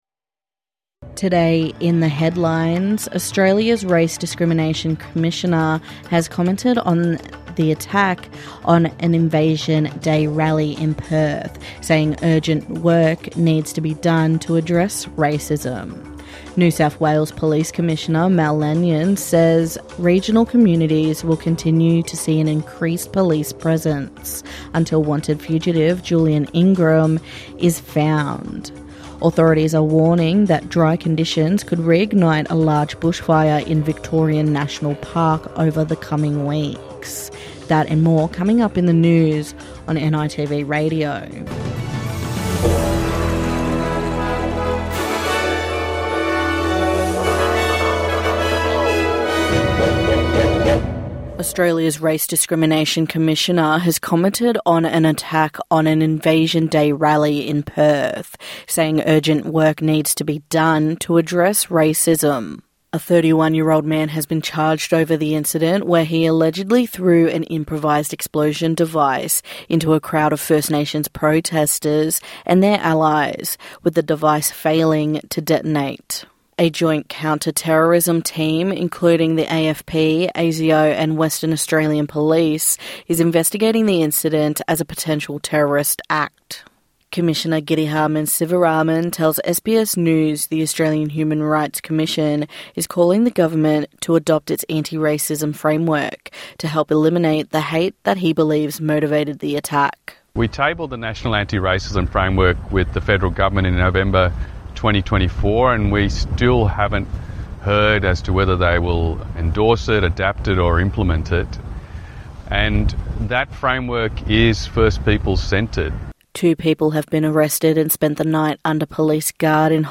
On the program voices from the community at the Melbourne Janurary 26 rally on the steps of Victoria Parliament House.